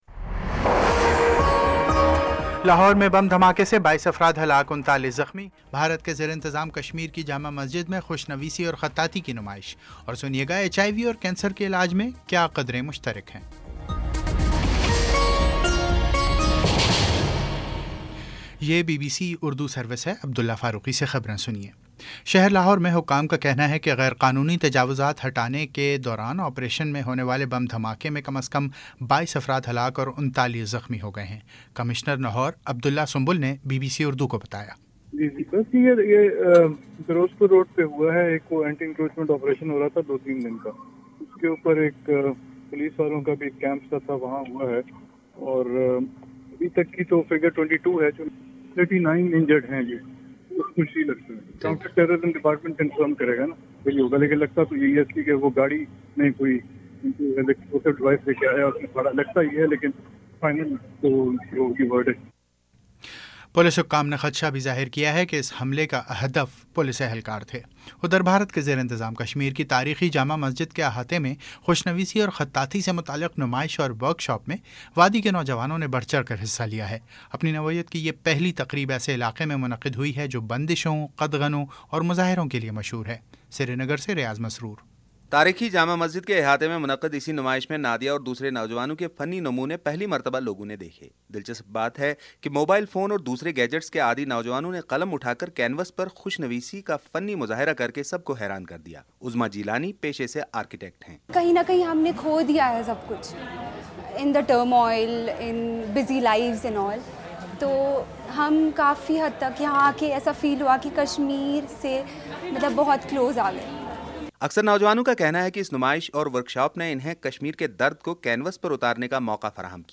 جولائی 24 : شام چھ بجے کا نیوز بُلیٹن